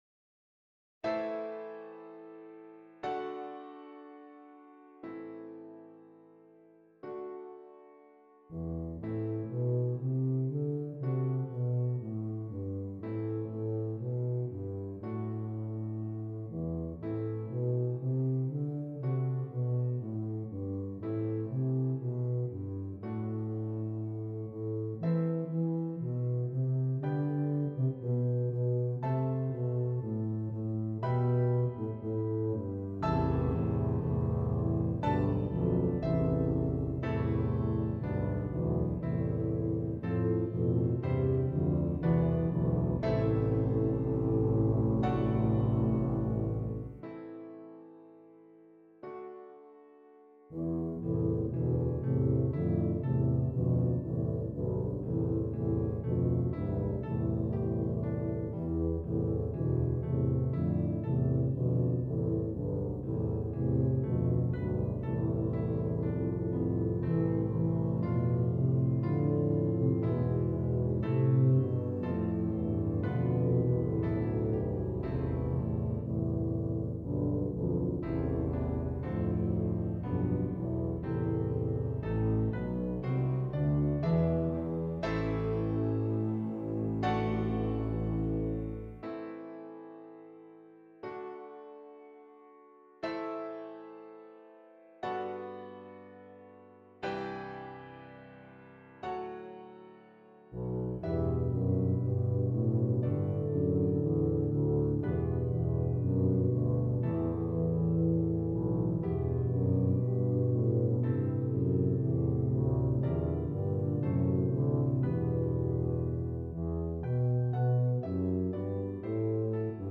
3 Tubas and Keyboard
Traditional